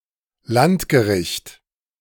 The Landgericht (German: [ˈlantɡəˌʁɪçt]
De-Landgericht.ogg.mp3